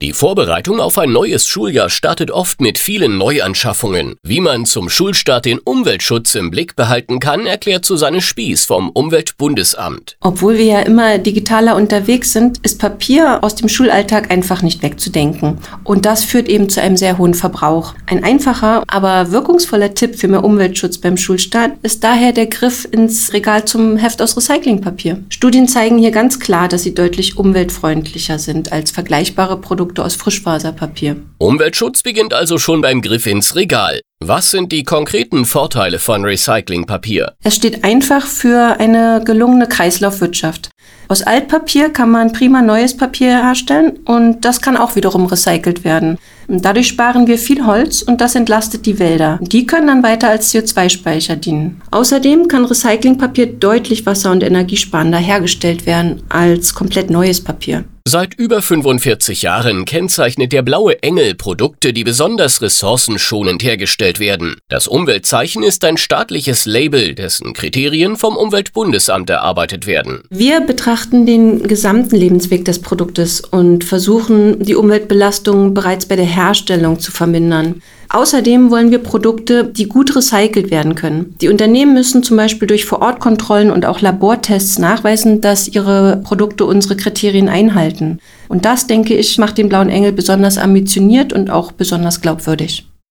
Radio contributions